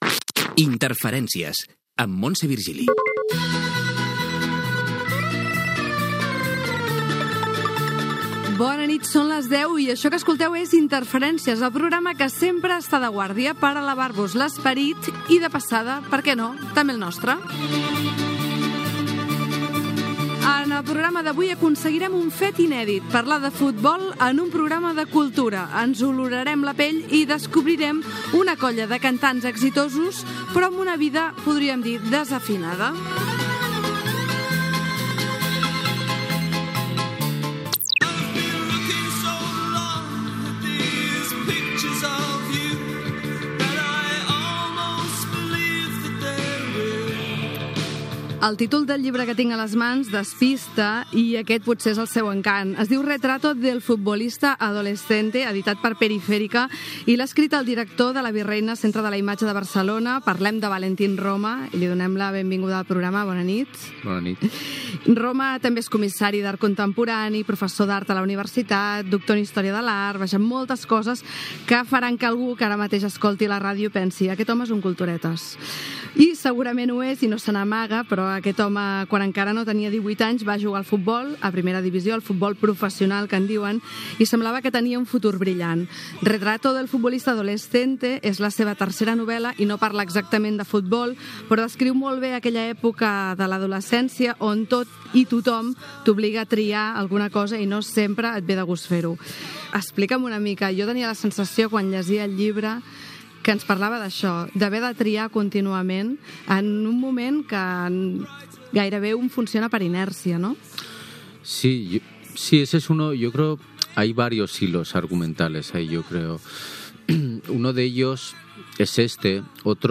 Indicatiu del programa, hora, presentació, entrevista sobre el llibre "Retrato del futbolista adolescente" de